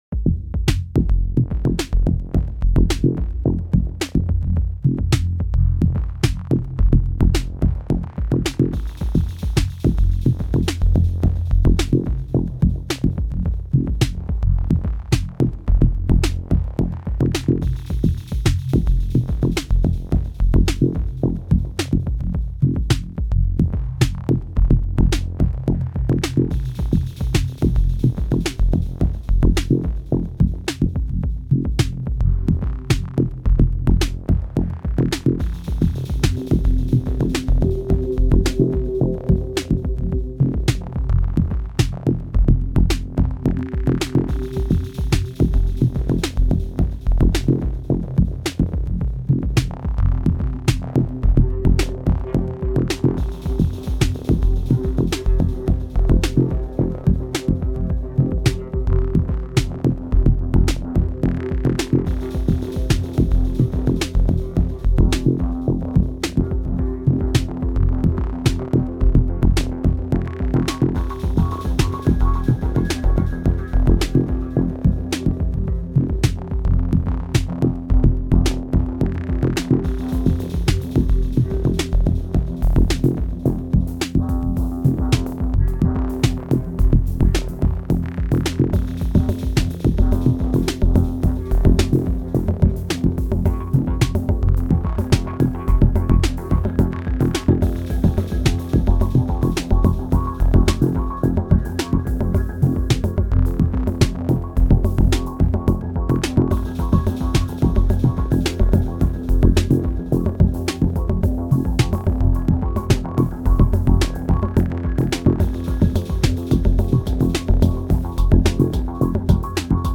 This my fourth jam with the Syntakt
The main lead/texture comes from modular, but everything else (percussions, bass and what not) is Syntakt.